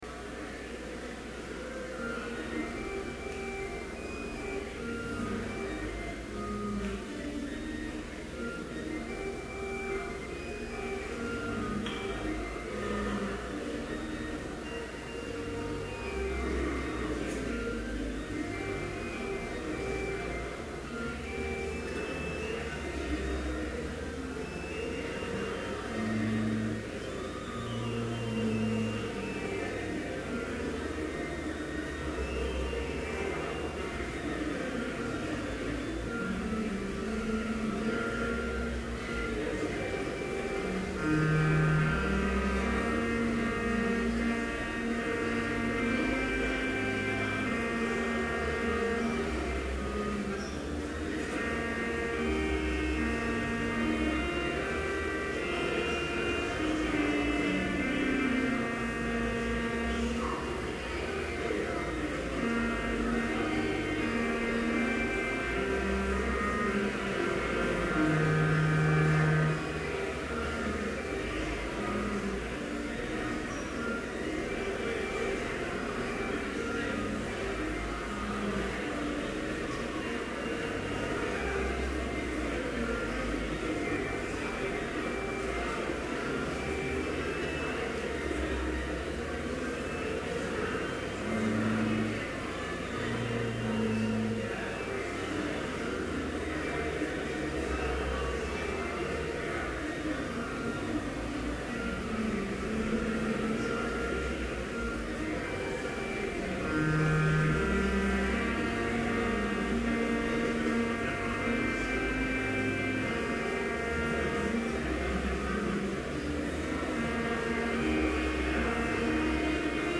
Complete service audio for Chapel - October 17, 2011
Prelude Hymn 518, vv. 1 & 3, Rise! To Arms! With Prayer Employ You Scripture Reading II Corinthians 10:3-5 Homily Prayer for Monday Morning Hymn 518, v. 4, Jesus, all Thy children… Benediction Postlude